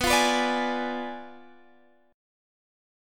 Bdim7 Chord (page 2)
Listen to Bdim7 strummed